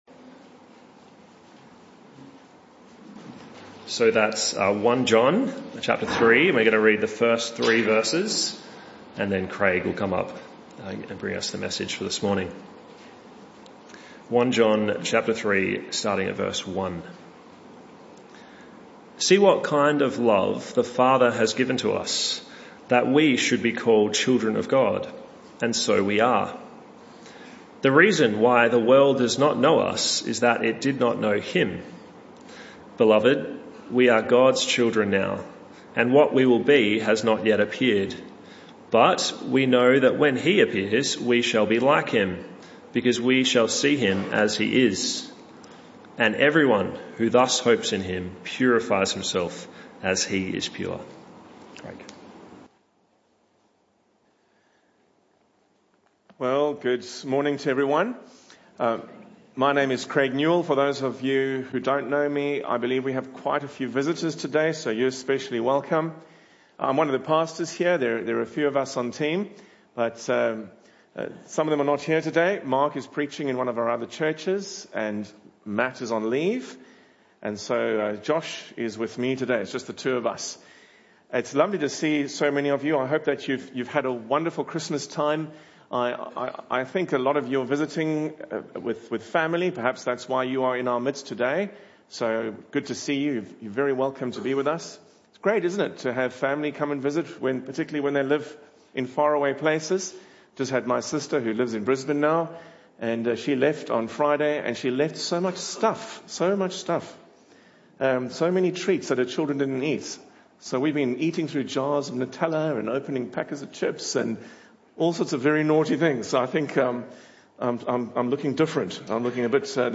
This talk was a one-off that took place in the AM Service.